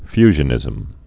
(fyzhə-nĭzəm)